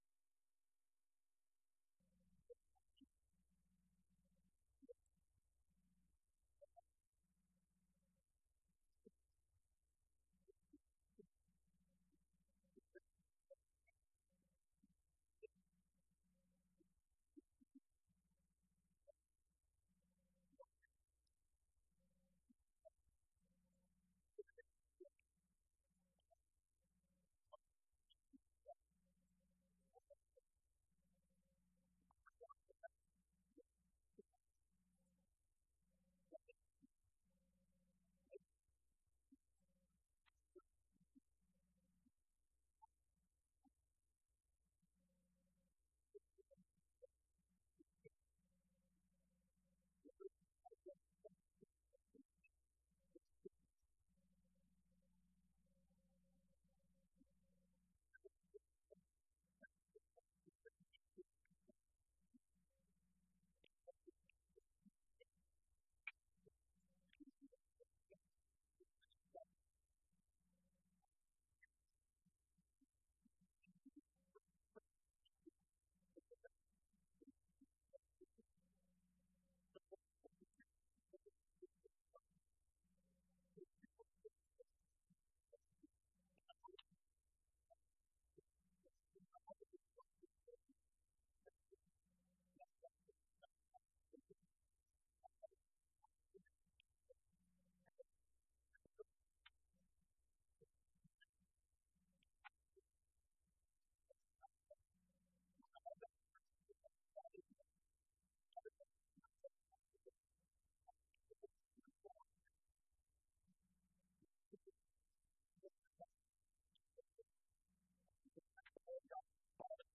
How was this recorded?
Event: 6th Annual Southwest Spiritual Growth Workshop